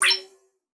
Game Notification 20.wav